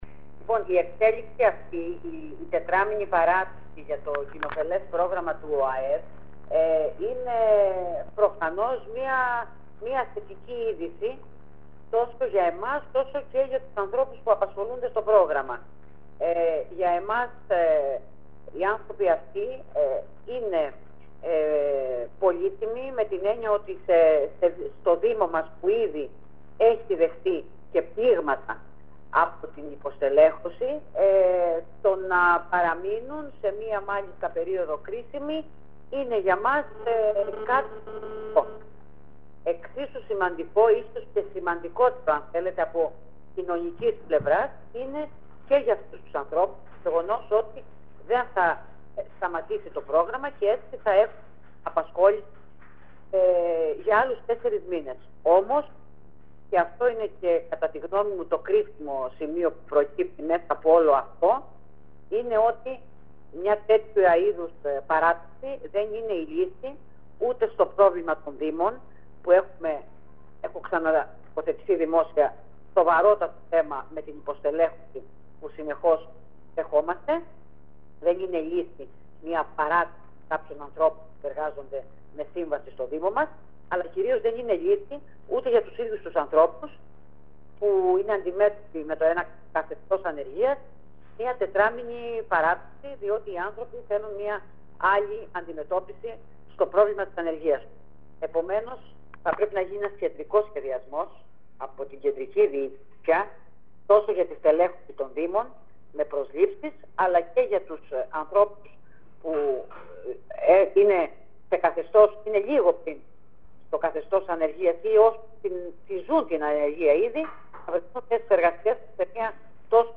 Μιλώντας στο iraklionews η Αντιδήμαρχος Διοικητικών και Οικονομικών Υπηρεσιών Ηρακλείου κ. Μαρία Καναβάκη ενημέρωσε πως πρόκειται συγκεκριμένα για 250 εργαζόμενους όλων των ειδικοτήτων που απασχολούνταν με αυτό το καθεστώς στο δήμο Ηράκλειο έως και τον Ιανουάριο.
Οι δηλώσεις της Αντιδημάρχου κ. Καναβάκη: